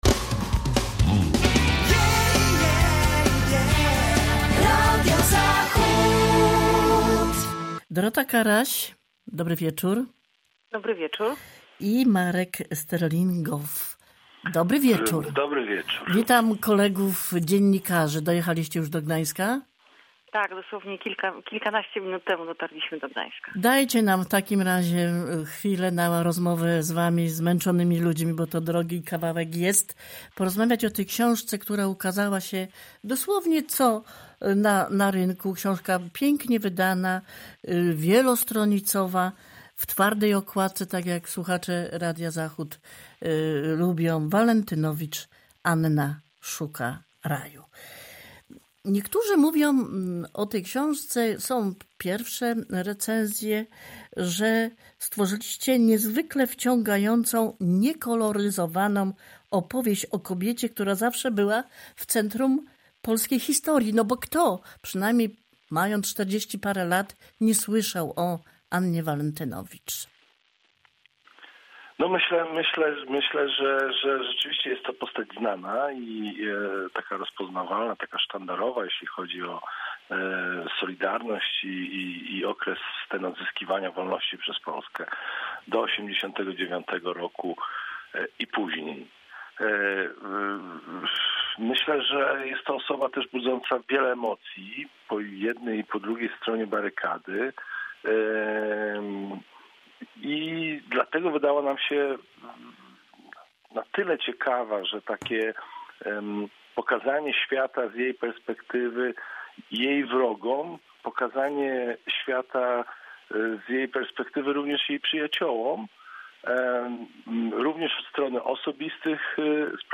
walentynowicz-rozmowa.mp3